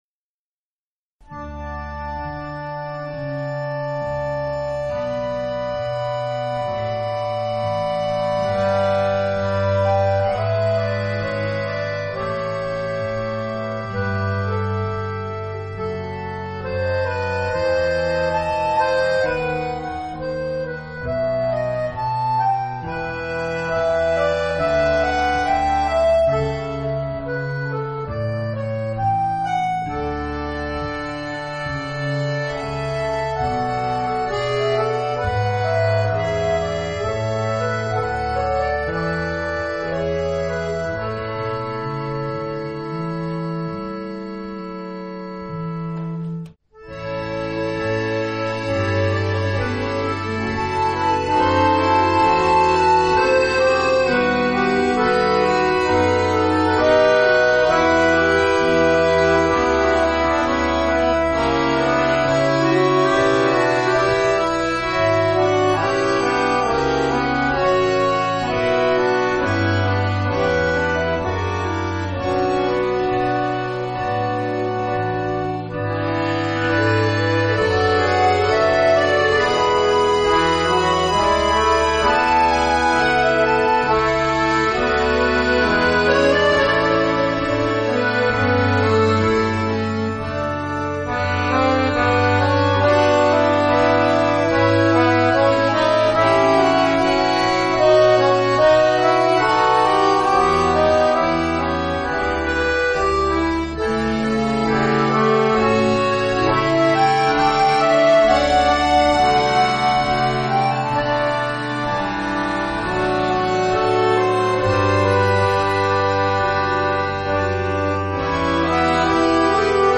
2004 – Akkordeonorchester Neustadt bei Coburg e. V.